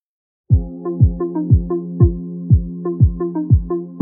ژانر: ریمیکس
پادکست هیپ‌هاپ و الکترونیک با میکس حرفه‌ای و کیفیت عالی MP3